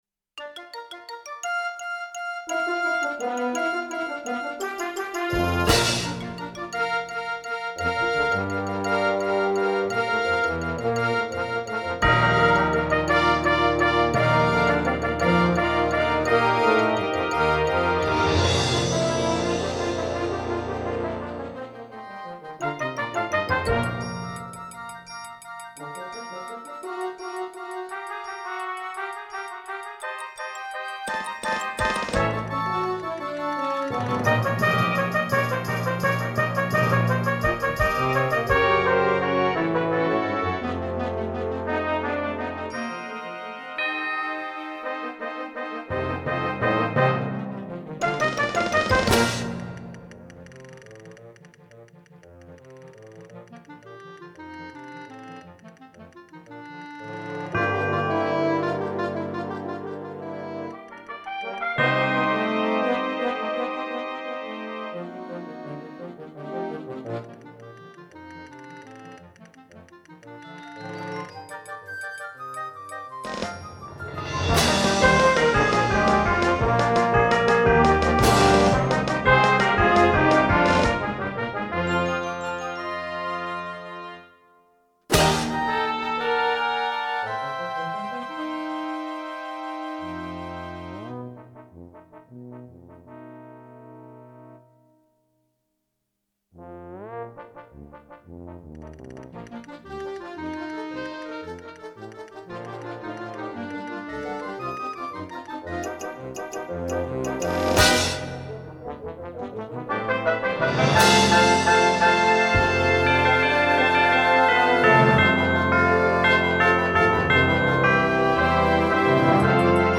Genre: Band
Percussion 1 (snare drum, tambourine, bass drum)
Percussion 3 (castanets, tambourine)